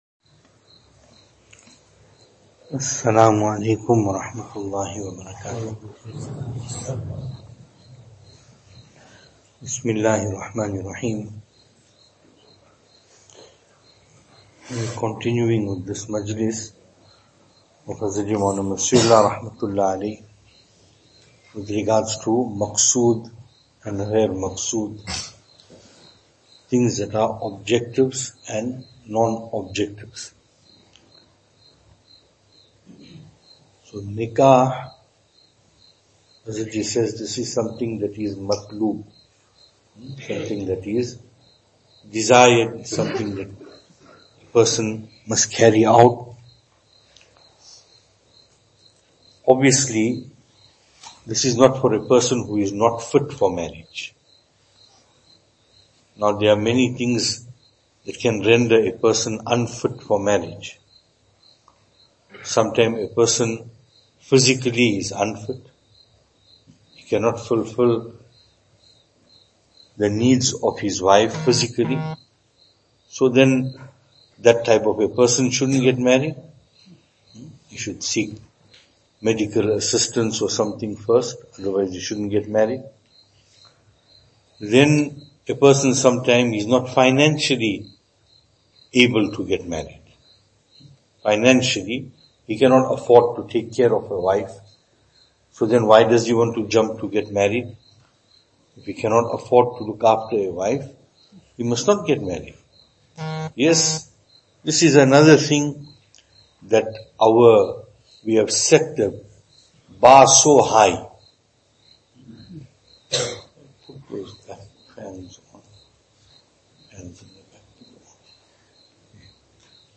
Treasure our senior Ulema Venue: Albert Falls , Madressa Isha'atul Haq Service Type: Zikr